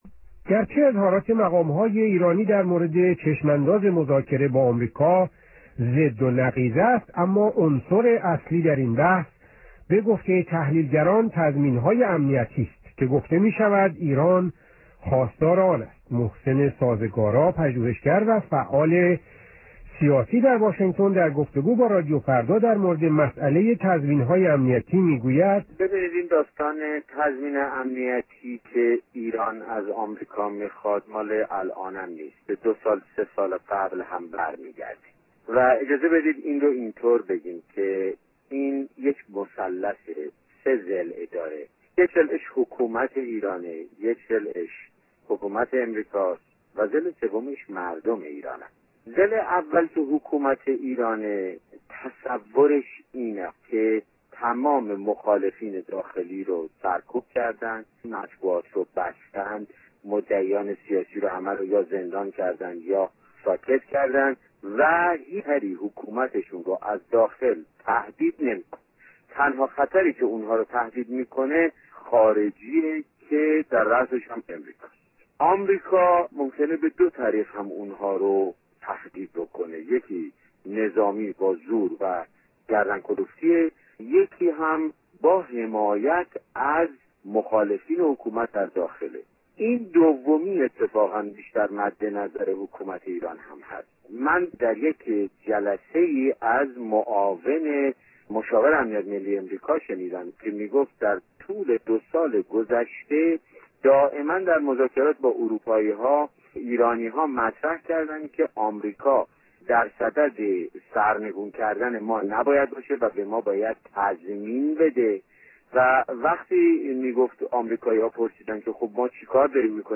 یکشنبه ۷ خردادماه ۱۳۸۵ - ۸:۵۷ بعدازظهر | مصاحبه ها
محسن سازگارا پژوهشگر و فعال سیاسی در آمریکا در مصاحبه با رادیو فردا می گوید: حکومت ایران تصور می کند که تمام مخالفان را سرکوب کرده، مطبوعات و مدعیان سیاسی را ساکت کرده و خطری حکومت را تهدید نمی کند جز خارجی ها و در صدر آنها آمریکا. حکومت ایران بیش از دخالت نظامی آمریکا از طرح مساله دموکراسی در ایران توسط مقامهای آمریکا می ترسد.